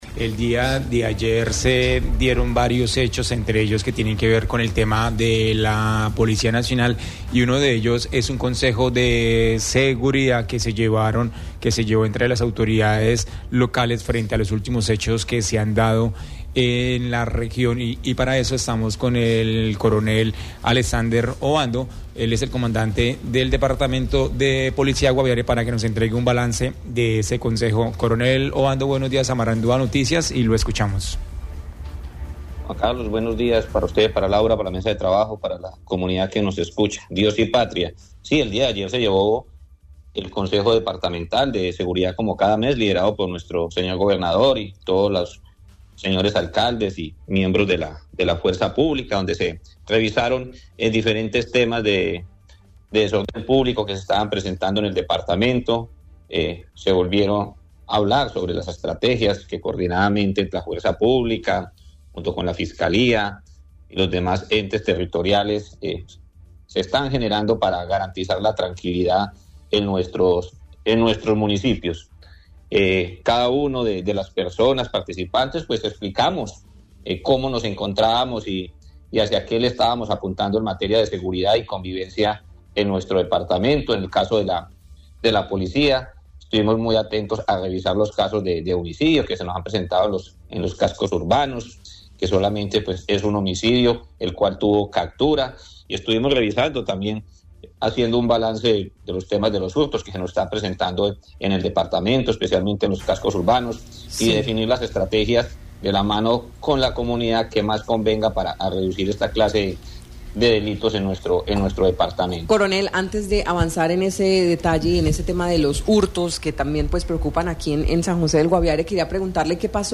En Marandua Noticias, hablamos con el coronel Alexander Obando, comandante del Departamento de Policía Guaviare, sobre el Consejo de Seguridad referente a los homicidios